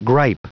Prononciation du mot gripe en anglais (fichier audio)
Prononciation du mot : gripe